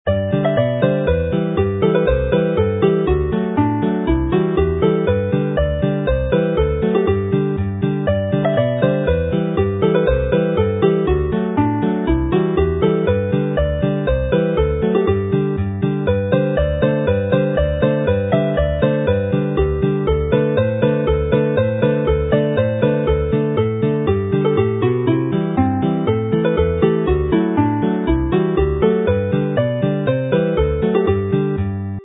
mp3 file as a polka, fast with chords